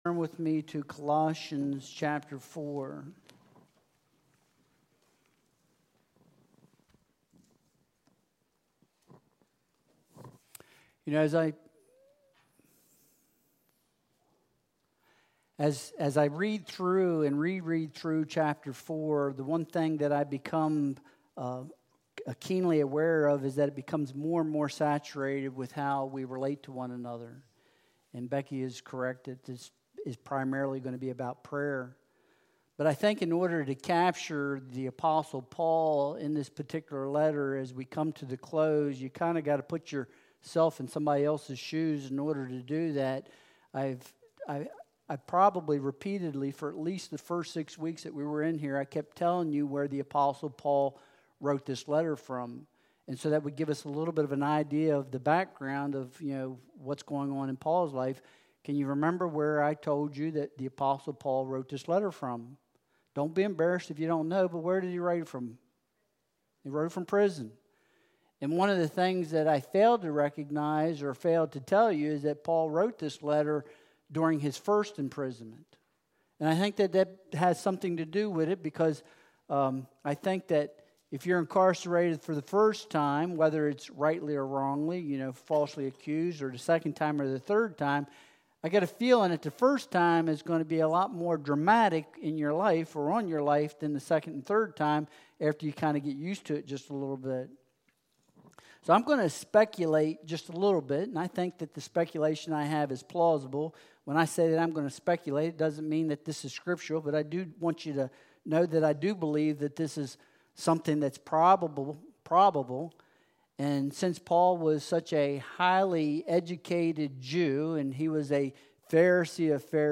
Colossians 4.2-18 Service Type: Sunday Worship Service Download Files Bulletin « Saul